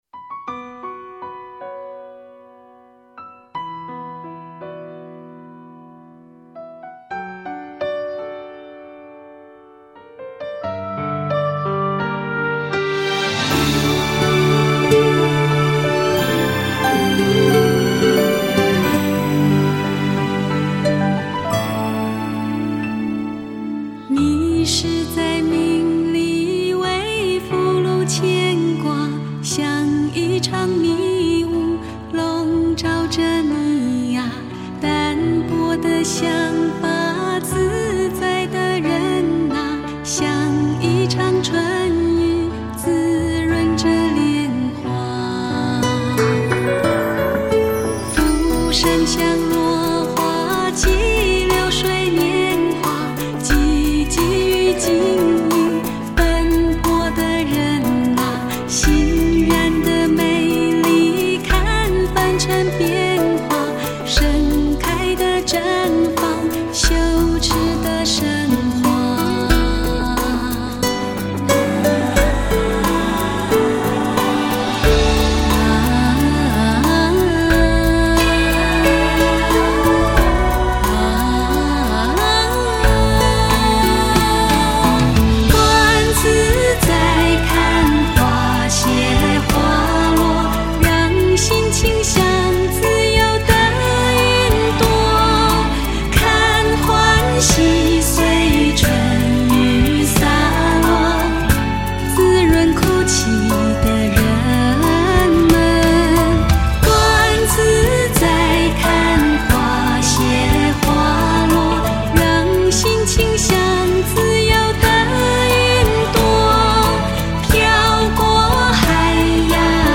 她的歌声让人有置身一片开阔的绿地。自然中带给人宁静与平和。